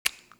DelayScrape.wav